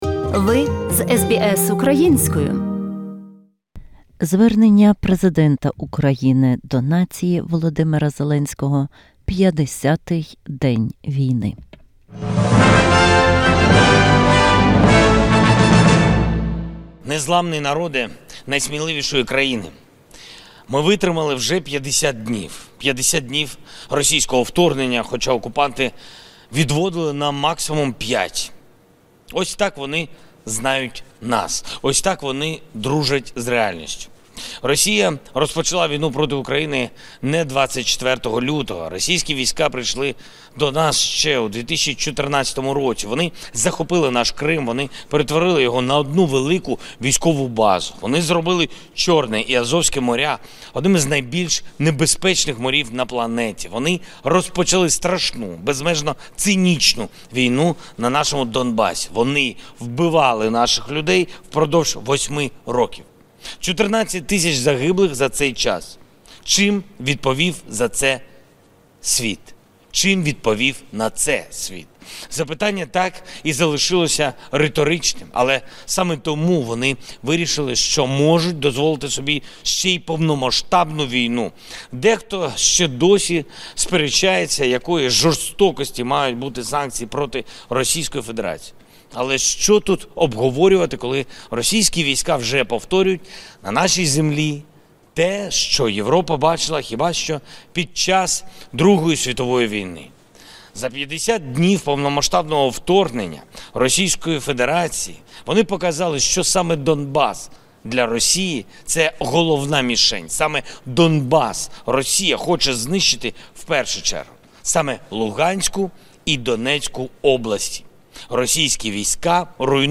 Звернення Президента України Володимира Зеленського.